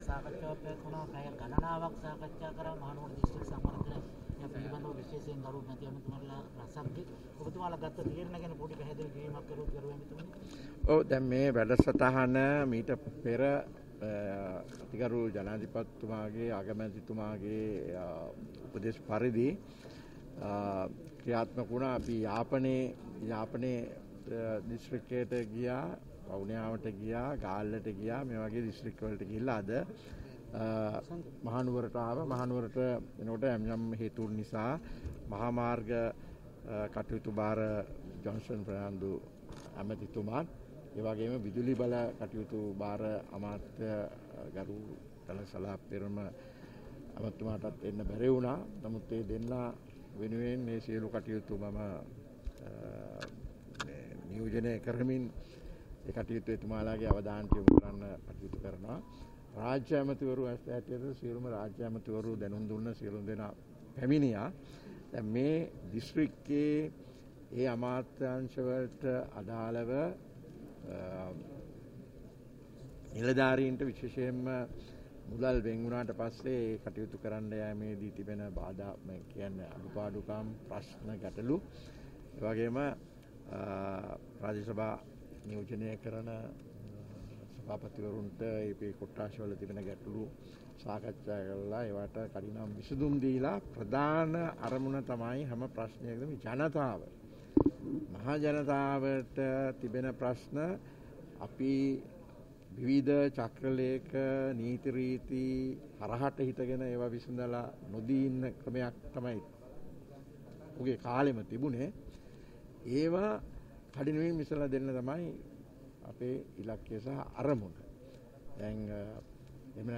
මහනුවර දිස්ත්‍රික් ලේකම් කාර්යාලයේදී ඊයේ (12) පැවැති ග්‍රාමීය යටිතල පහසුකම් සංවර්ධන කමිටු රැස්වීමෙන් අනතුරුව මාධ්‍ය හමුවේ අදහස් දක්වමිනි.